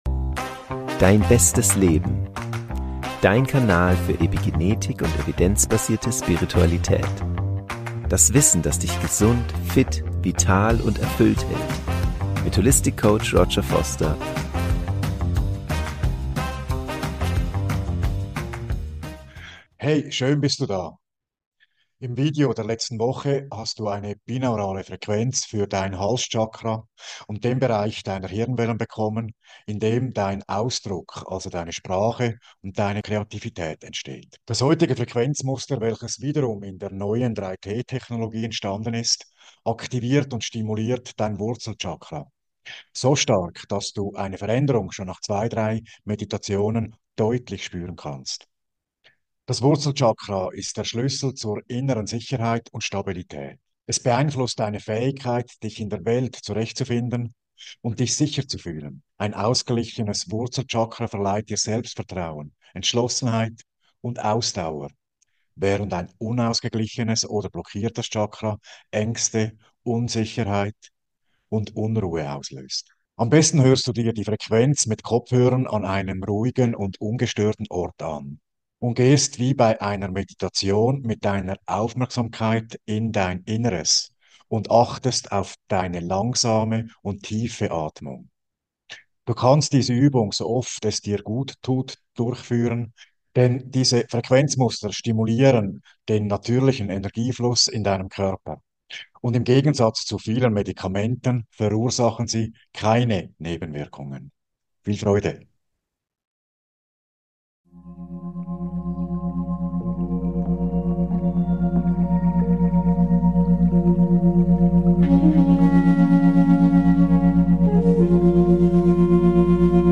Aktivierungsfrequenz Wurzel-Chakra | Meditation für Körper, Geist & Seele ~ Dein bestes Leben: Evidenzbasierte Spiritualität und Epigenetik Podcast
Für die optimale Wirkung, höre die Frequenz mit Kopfhörern an einem ruhigen und ungestörten Ort an.